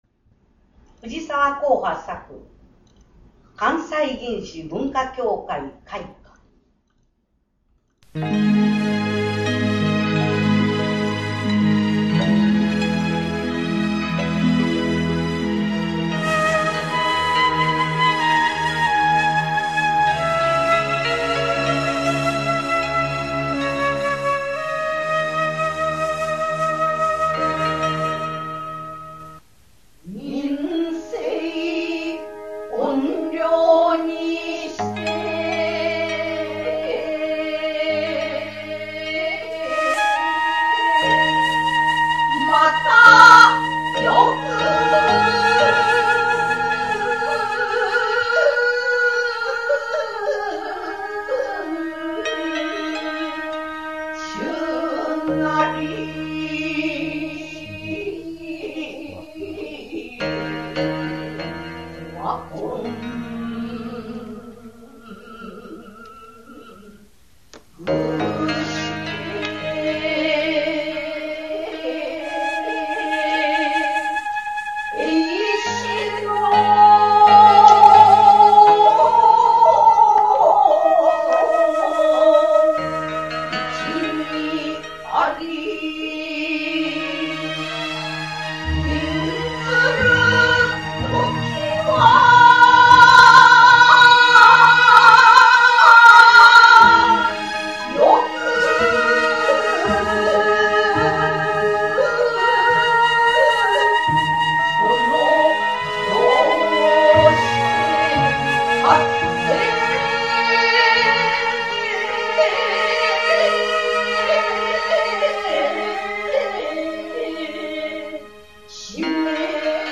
吟者：教養指導部（女性）